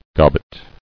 [gob·bet]